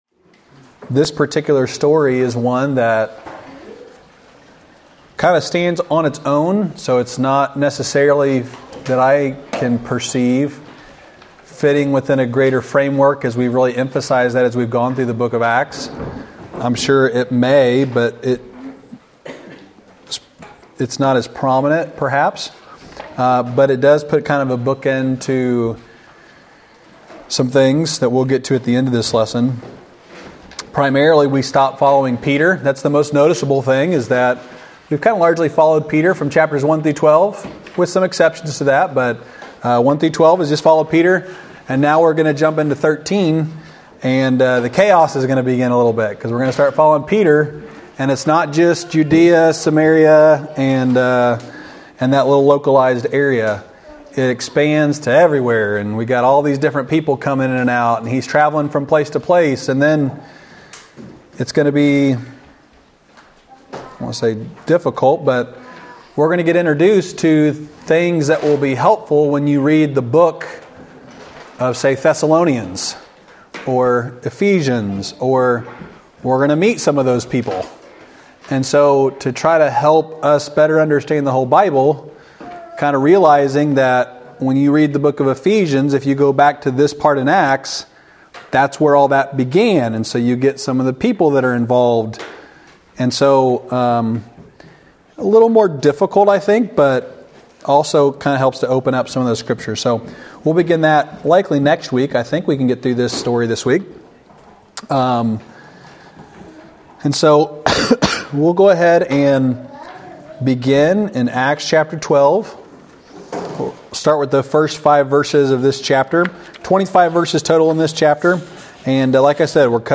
Wednesday night lesson from February 7, 2024 at Old Union Missionary Baptist Church in Bowling Green, Kentucky.